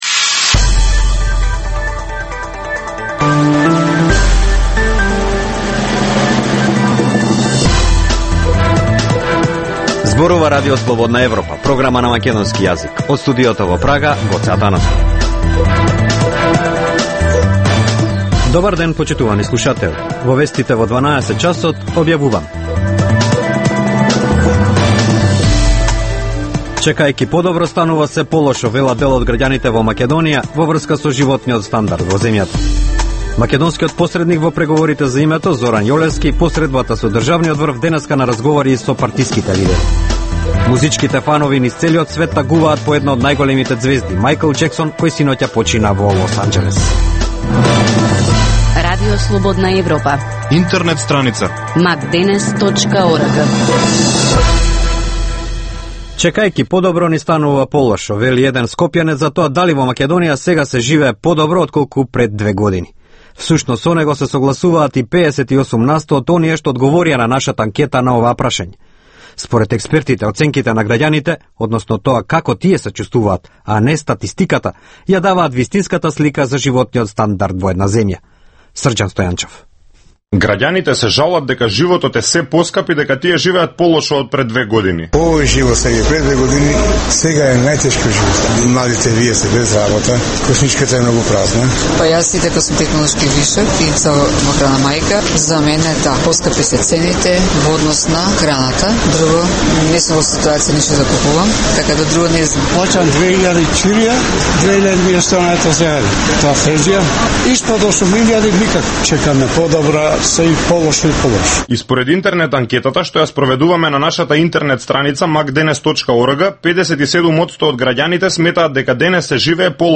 Информативна емисија, секој ден од Студиото во Прага. Топ вести, теми и анализи од Македонија, регионот и светот. Во Вестите во 12 часот доминантни се актуелните теми од политиката и економијата.